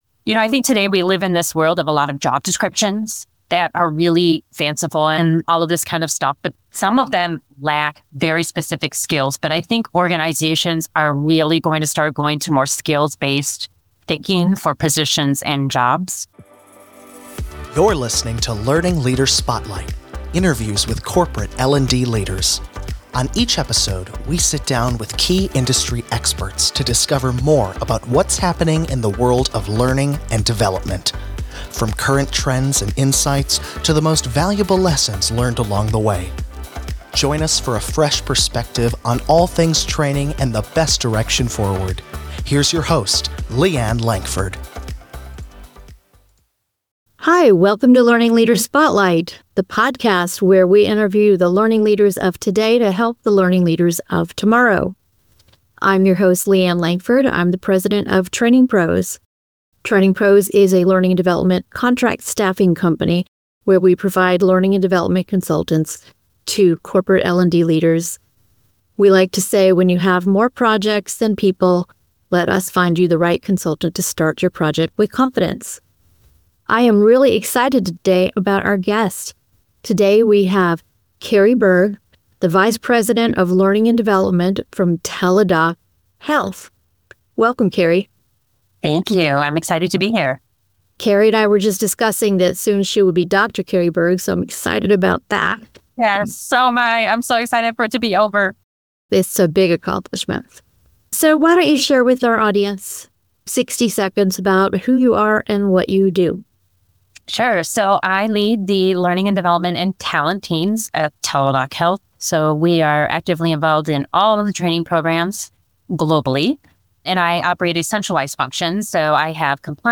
Interviews with corporate L&D leaders